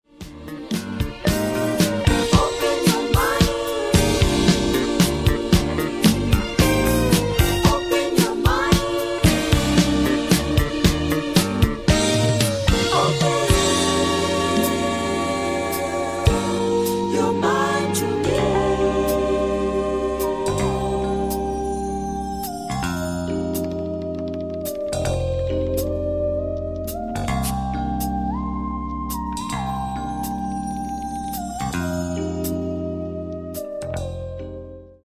Genere:   Jazz Funk